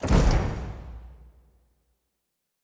Fantasy Interface Sounds
Special Click 17.wav